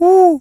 Animal_Impersonations
owl_hoot_02.wav